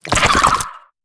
Sfx_creature_penguin_call_01.ogg